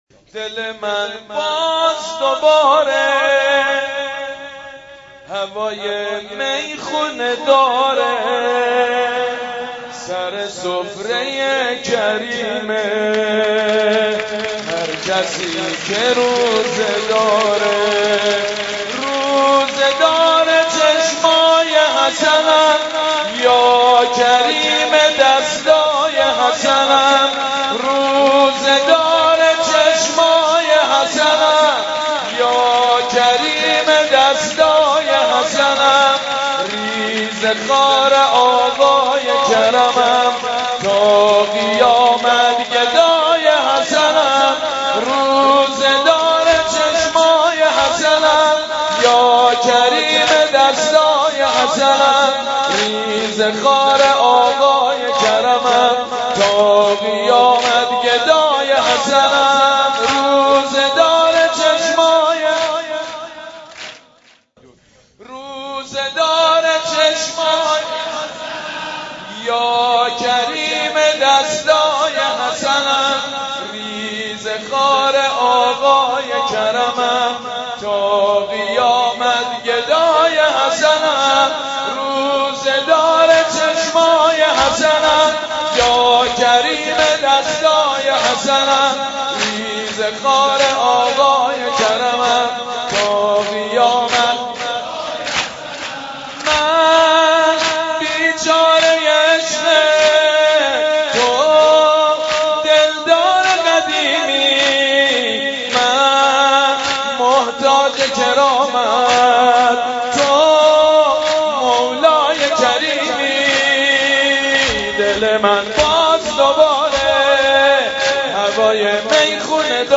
مولودی امام حسن مجتبی سید مجید بنی فاطمه